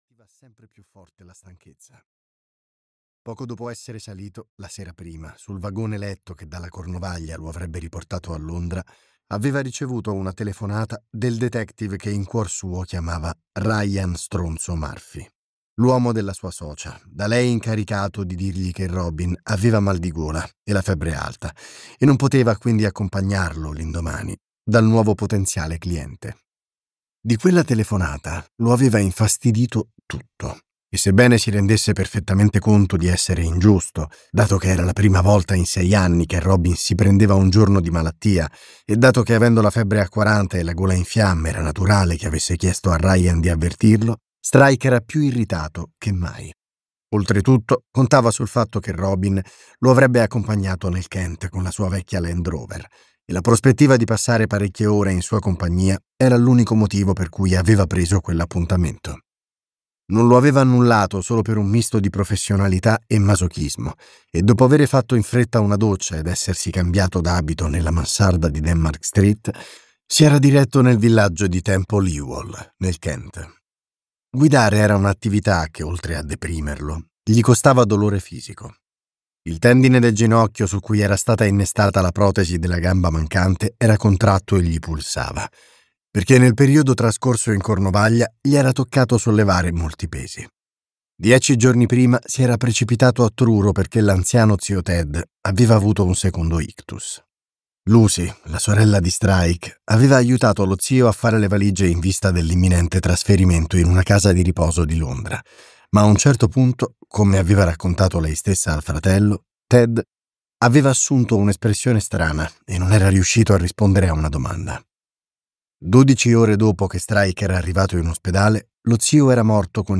"L'uomo marchiato" di Robert Galbraith - Audiolibro digitale - AUDIOLIBRI LIQUIDI - Il Libraio